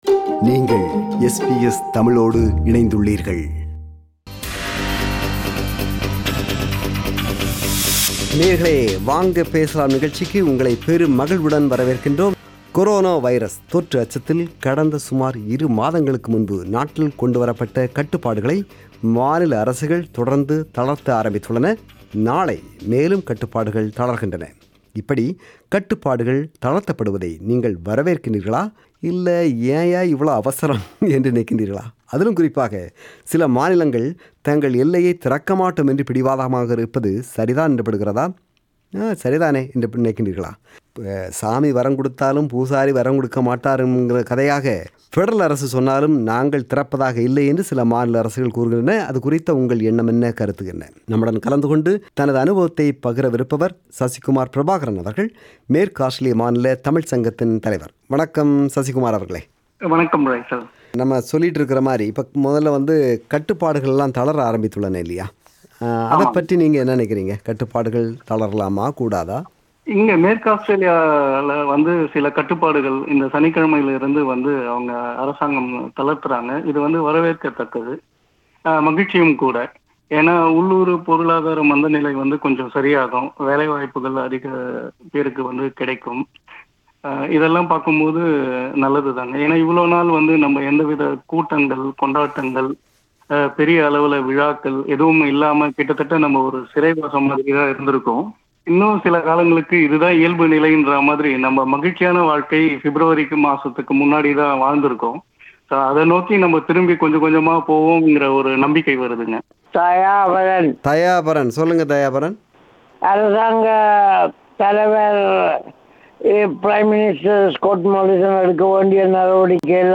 Listeners shared their views on states relaxing COVID 19 related restrictions.